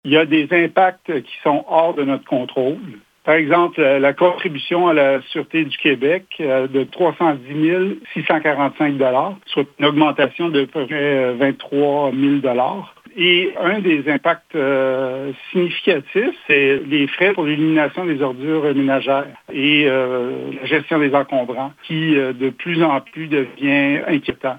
Selon le maire de Lac-Sainte-Marie, Marc Beaudoin, plusieurs circonstances ne leur ont pas donné suffisamment de marge de manœuvre :